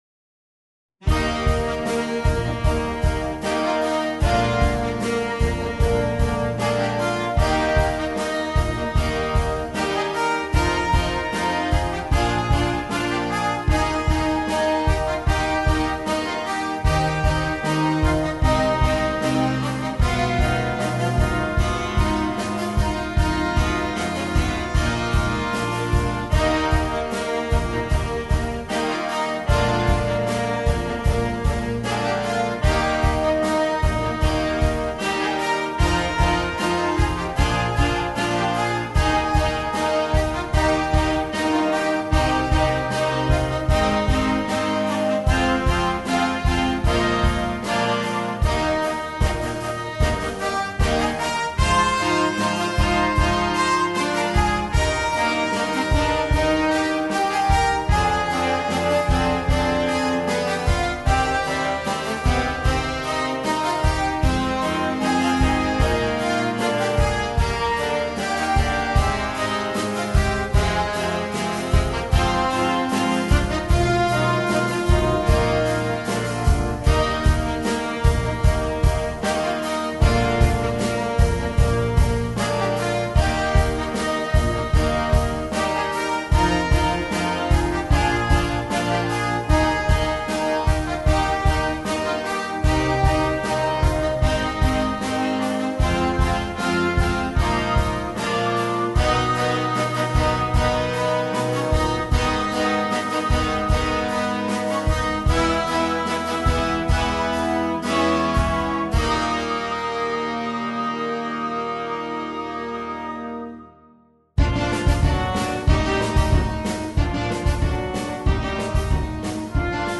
Per organico variabile a 5 voci